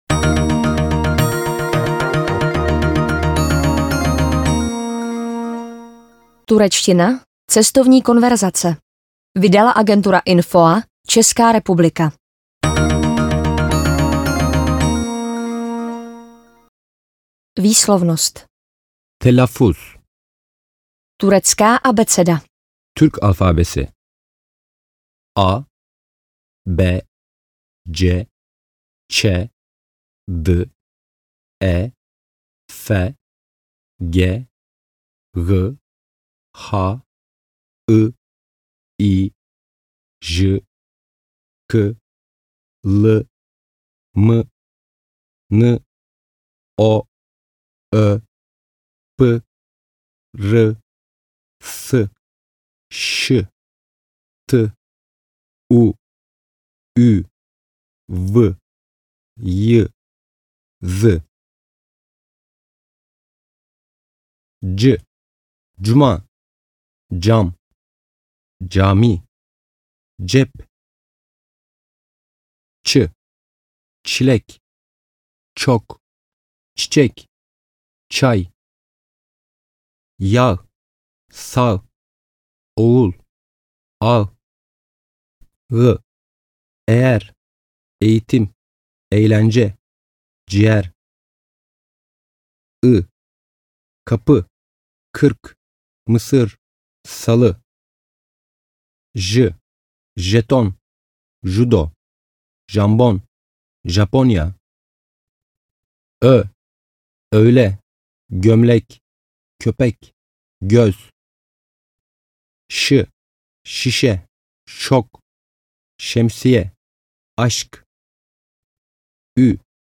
AudioKniha ke stažení, 32 x mp3, délka 51 min., velikost 27,5 MB, česky